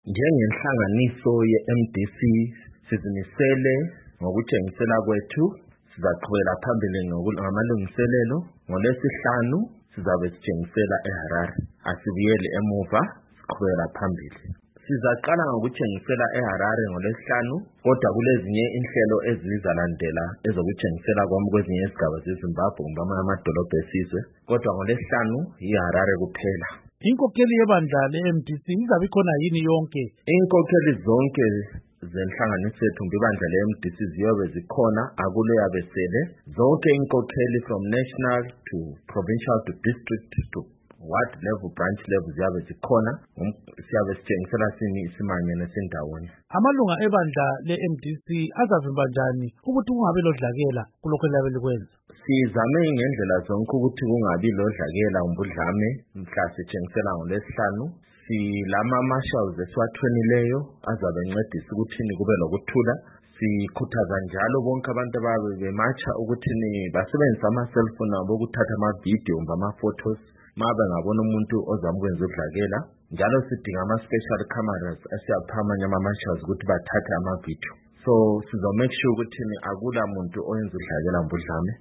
Ingxoxo Esiyenze LoMnu. Daniel Molokele